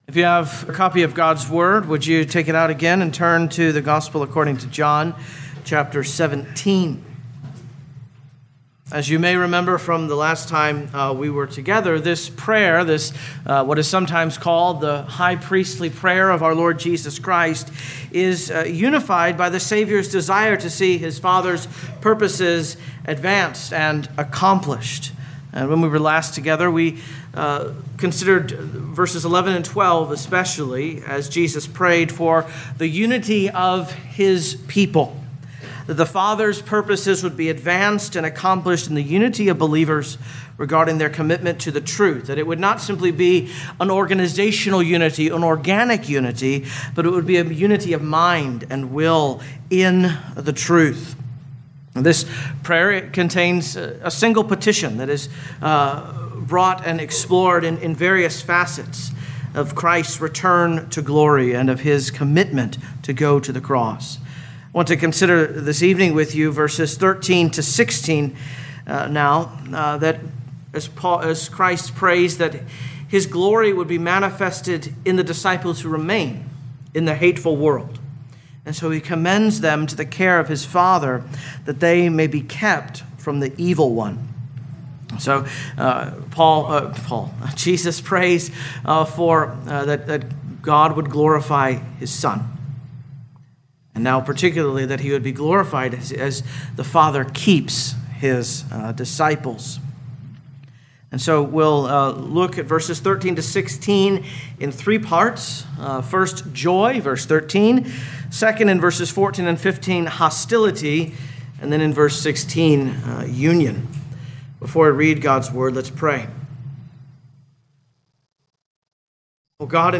2025 John Evening Service Download
You are free to download this sermon for personal use or share this page to Social Media. Disciples in the World Scripture: John 17:13-16